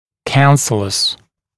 [ˈkænsələs][ˈкэнсэлэс]губчатый, решётчатый, сетчатый